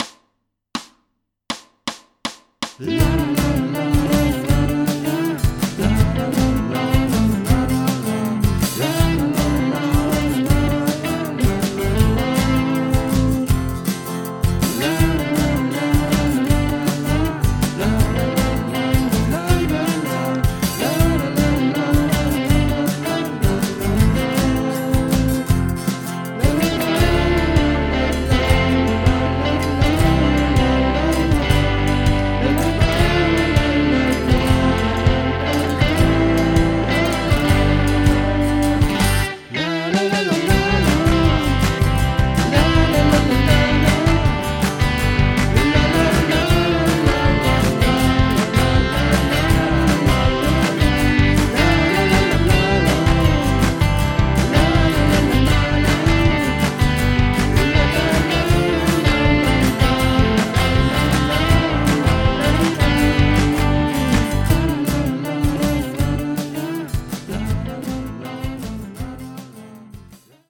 Deshalb jetzt mit meinem ganz ursprünglichen "Lala" vom frühen Morgen des 25.04. Vermutlich wird es den ein oder anderen laut auflachen lassen, denn schön geht anders.
Was das Mitsingen angeht, müsste man vermutlich noch über eine Transponierung nachdenken, die untere Oktave ist für Frauen zu tief, die obere für die meisten Männer zu hoch - obwohl sich die gesamten Linien in weniger als einer Oktave abspielen. Und wie ich ja schon sagte: Die Nummer soll so in Richtung middle-of-the-road-american-modern-cuntry gehen, das kann man an sich auch aus dem ansonsten null produzierten Layout so raushören.